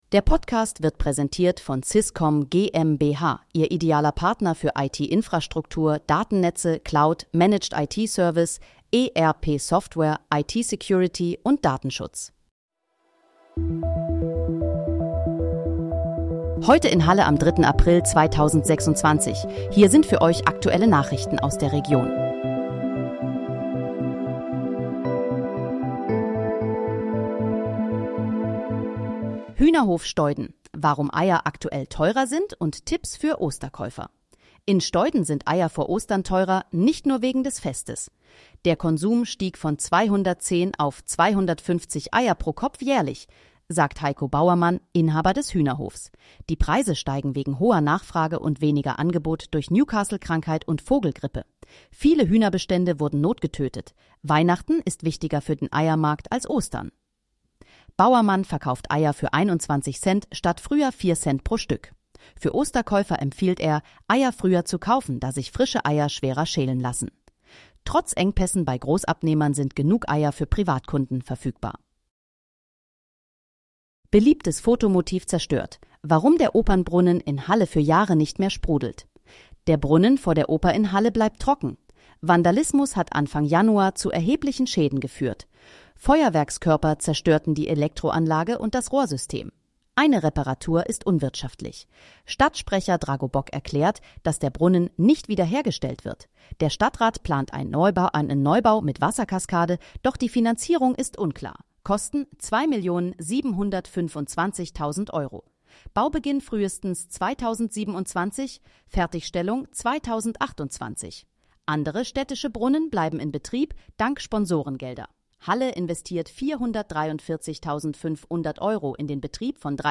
Heute in, Halle: Aktuelle Nachrichten vom 03.04.2026, erstellt mit KI-Unterstützung
Nachrichten